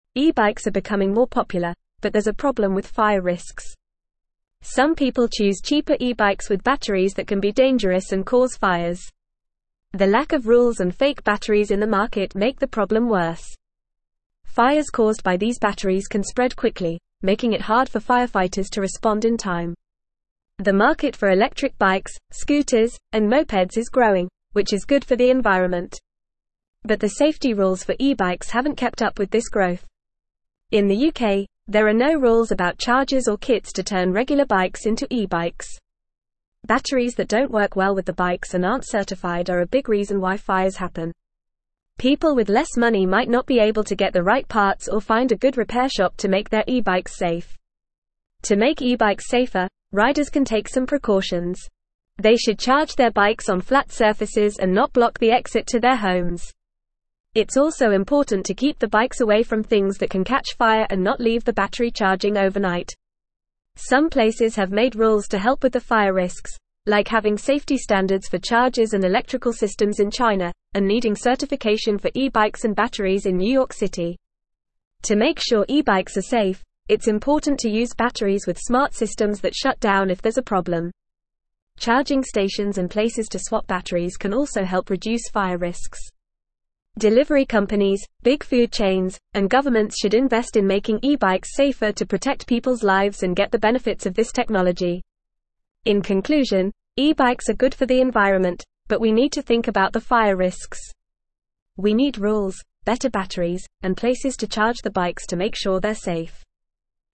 Fast
English-Newsroom-Upper-Intermediate-FAST-Reading-Risks-and-Regulations-E-Bike-Safety-Concerns-and-Solutions.mp3